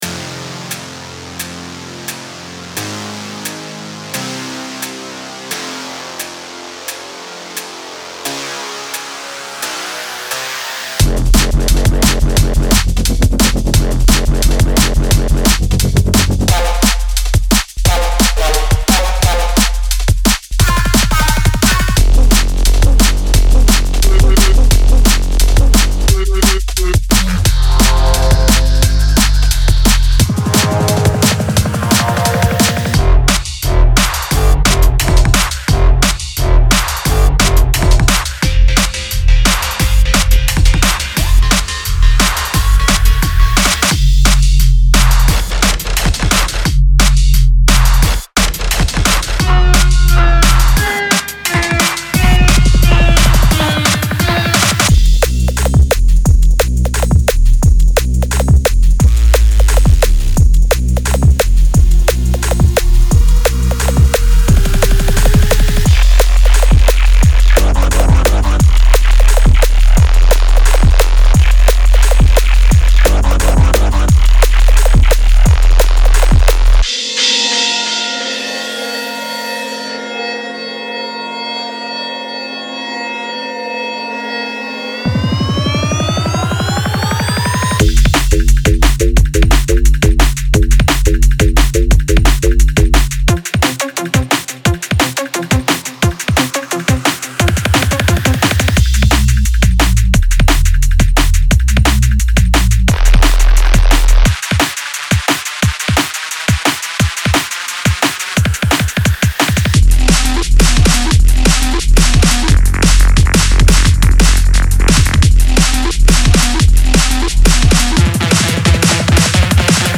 Genre:Drum and Bass
デモサウンドはコチラ↓
24Bit 44.1KHZ
62 Synth Loops
30 Bass Loops
10 Full Drum Loops